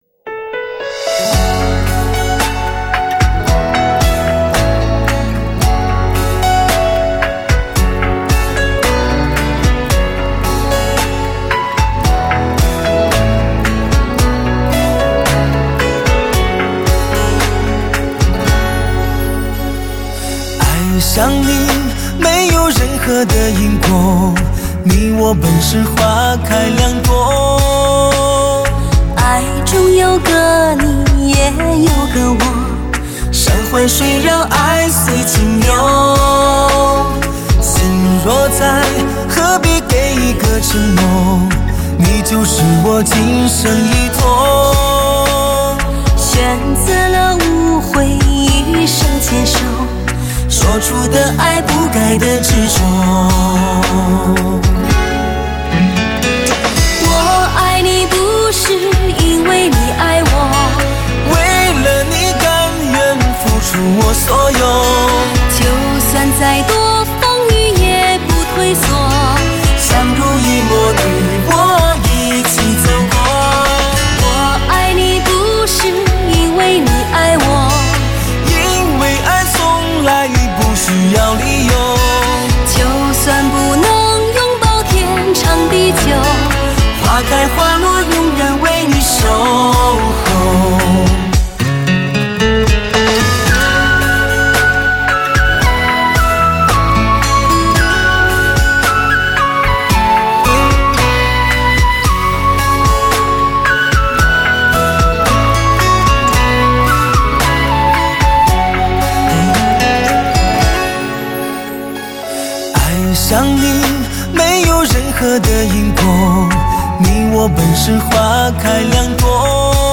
故事如雀屏，嗓音若沙雁，尘世像花园，爱情似飞鸟。
温暖的对唱情歌，美妙动听，用男女最深层的情愫诠释着所有人的爱情感伤，